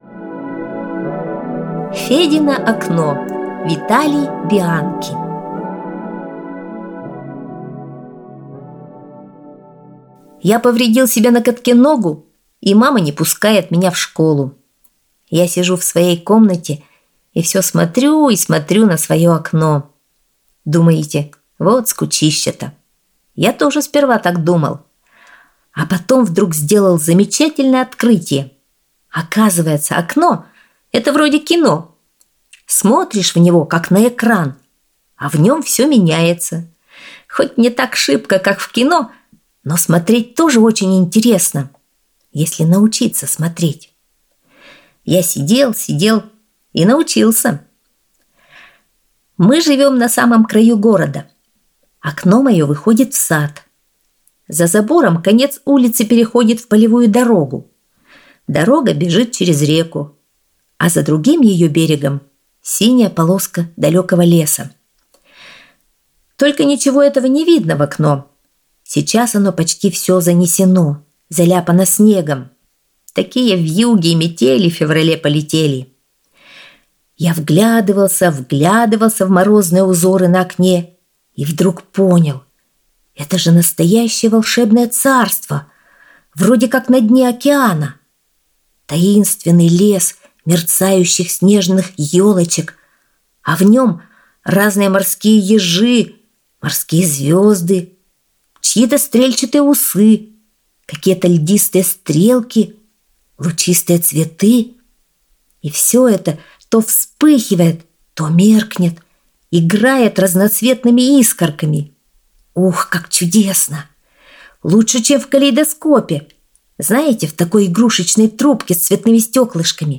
Аудиорассказ «Федино окно»